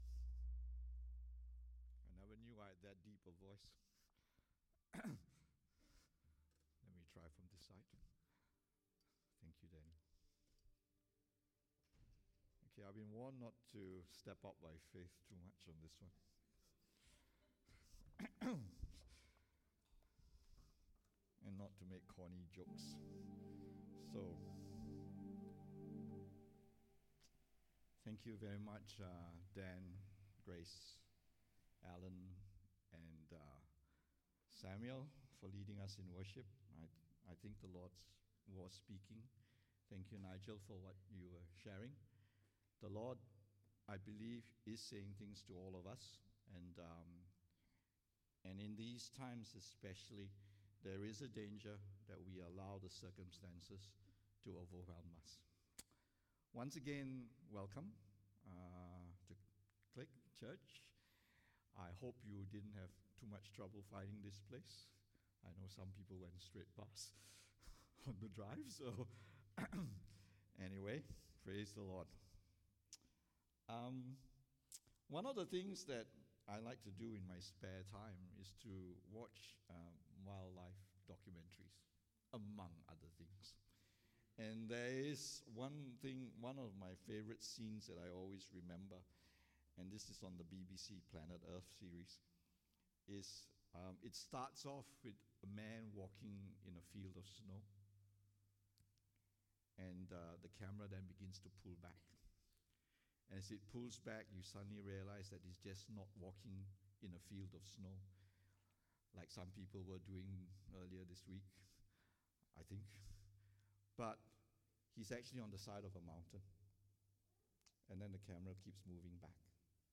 English Worship Service - 14th August 2022